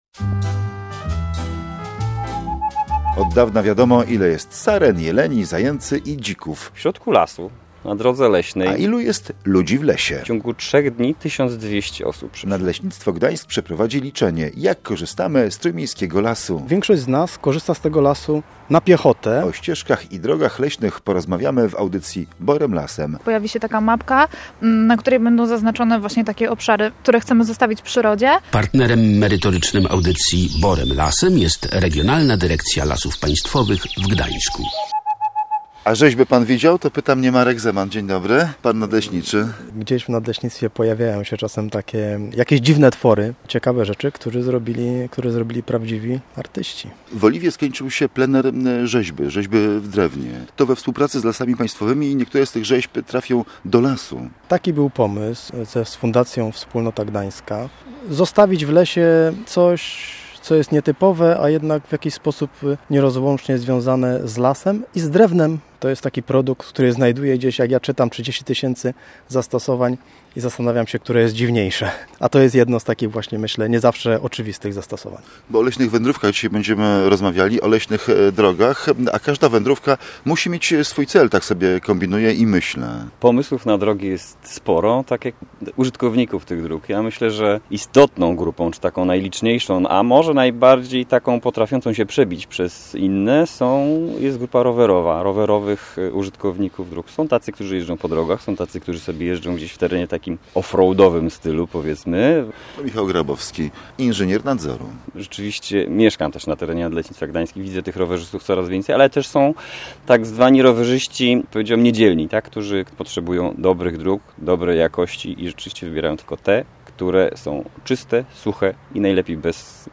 Posłuchaj rozmowy o tym kto chodzi po lesie, dlaczego potrzebne jest liczne w TPK, a może zostaniesz wolontariuszem i dołączysz do leśników?